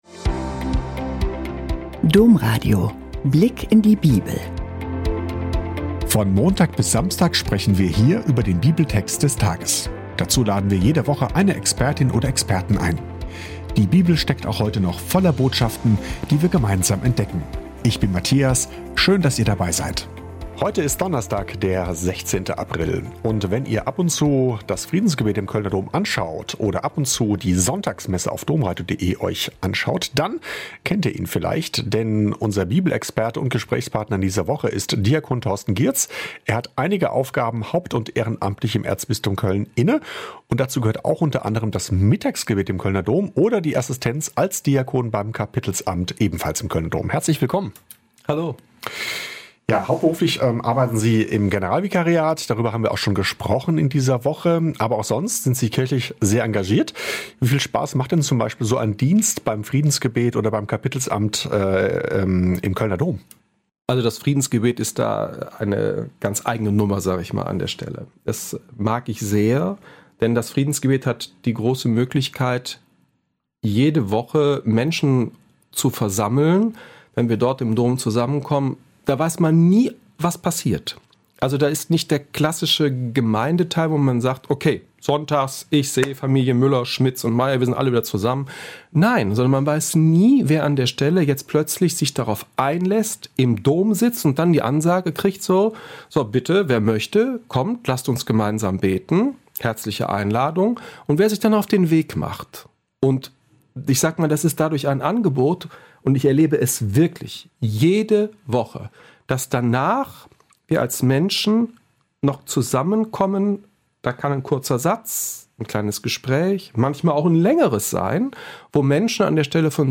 Was zählt wirklich? - Gespräch